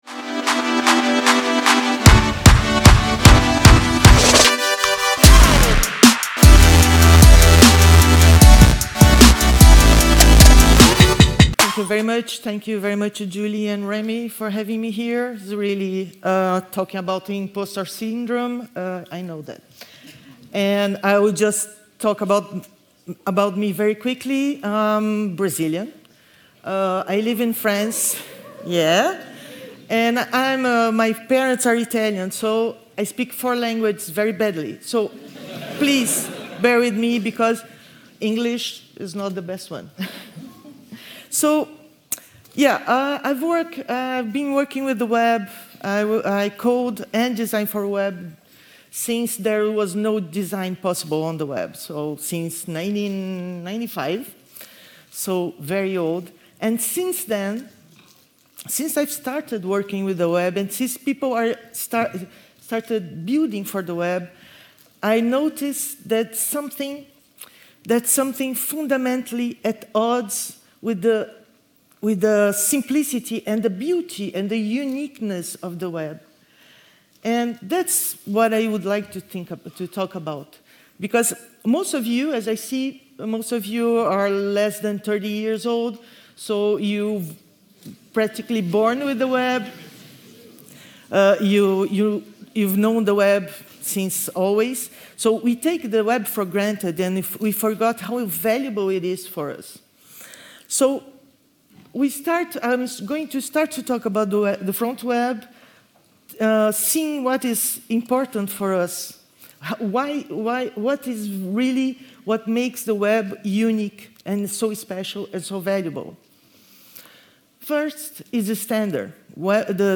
FF Conf - the UK's best JavaScript and Web conference in Brighton